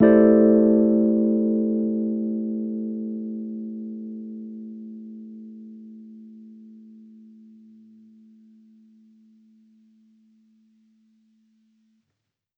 Index of /musicradar/jazz-keys-samples/Chord Hits/Electric Piano 1
JK_ElPiano1_Chord-Am11.wav